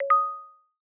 ReceivedMessage-f18b46.wav